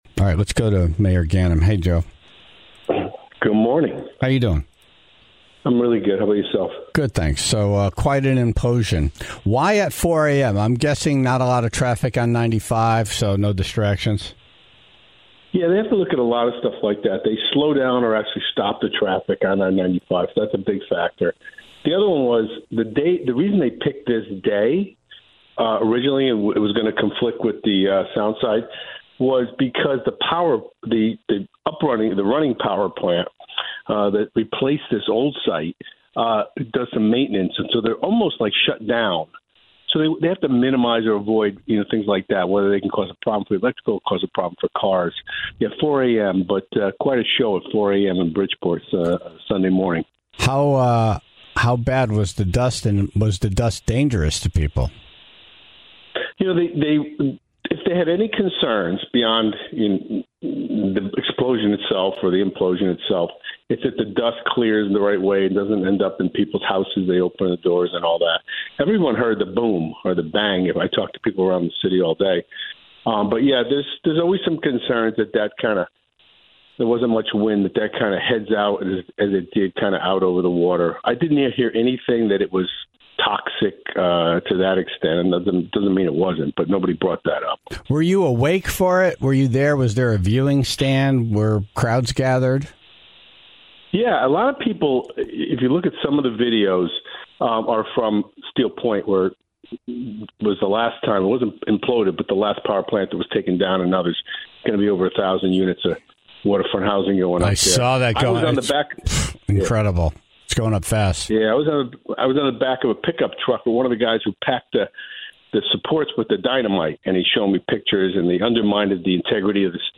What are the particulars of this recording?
called in to advocate for the city, and derail the show with his thoughts and stories.